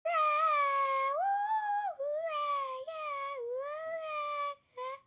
Cheering Sound Sound Effects Free Download
cheering sound sound effects free download